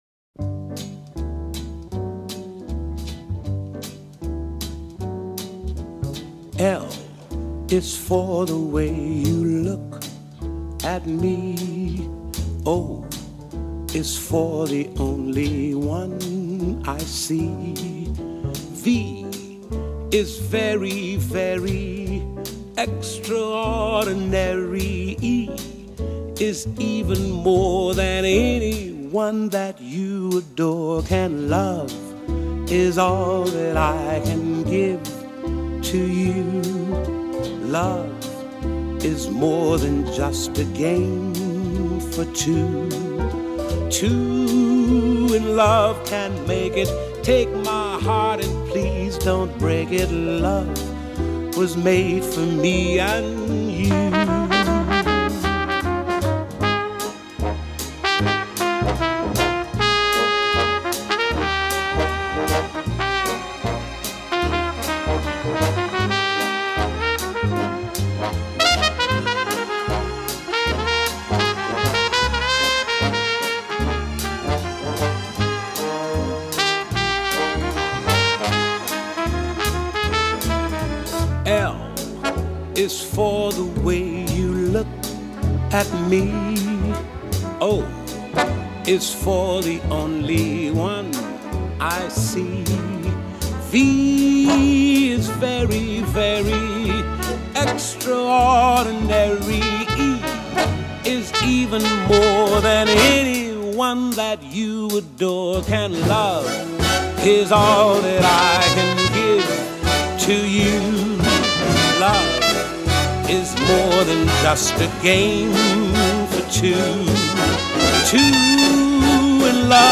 Mom & Son Vocal Duo
Acoustic duo from Buffalo, NY and Nashville, TN.